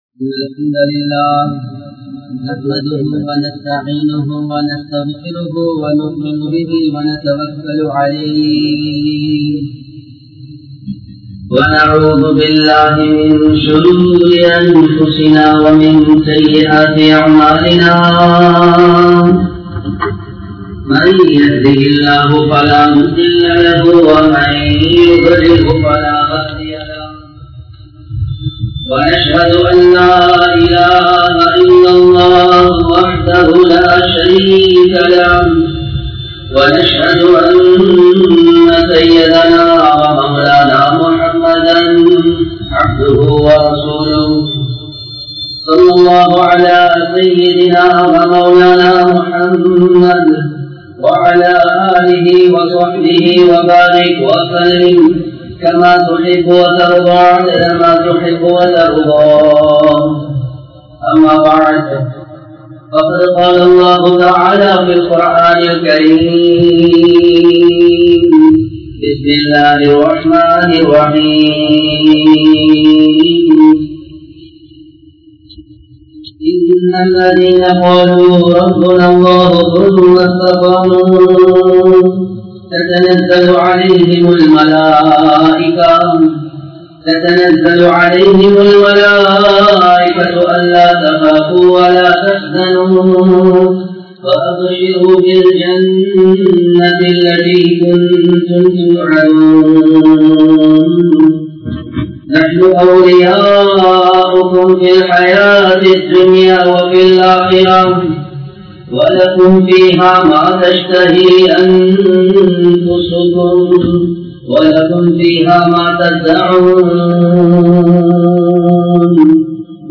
Islam Koorum Illara Uravu (இஸ்லாம் கூறும் இல்லற உறவு) | Audio Bayans | All Ceylon Muslim Youth Community | Addalaichenai